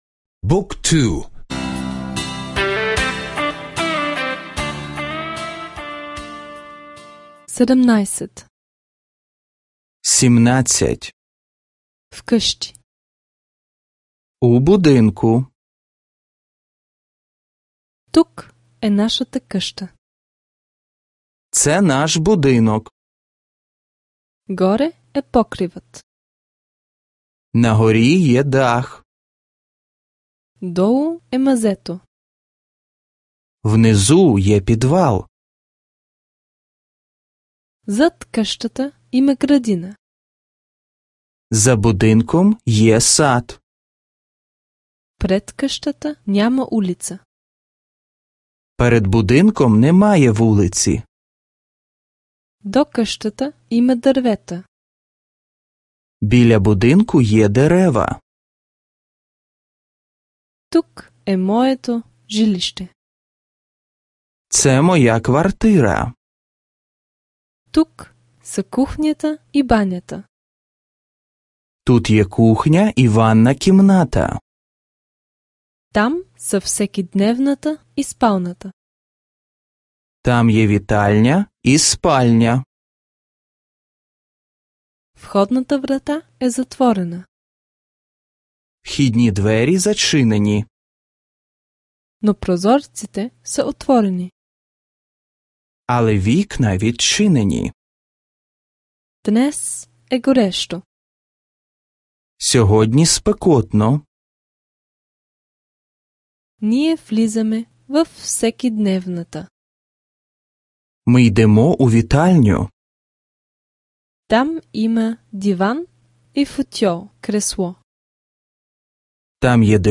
Аудиокурс по украински език (за слушане онлайн)